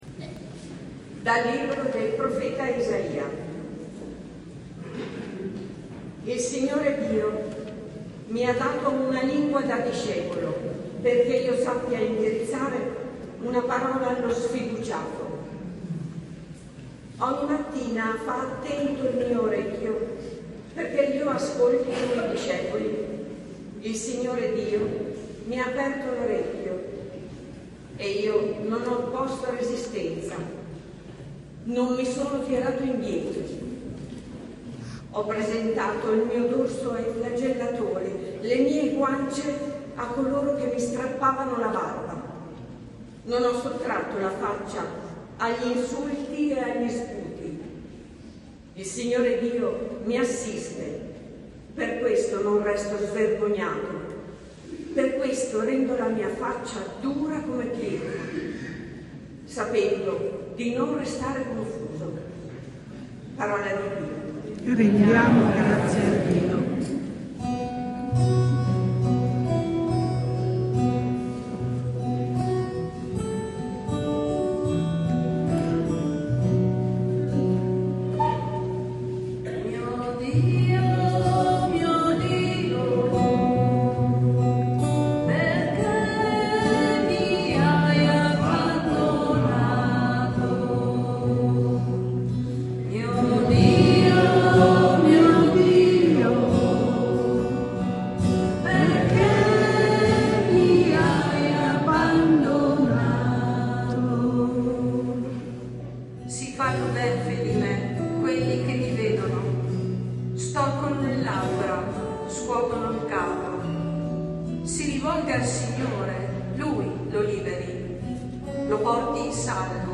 Letture + Passio + Omelia Domenica delle Palme – Anno A – Parrocchia San Pellegrino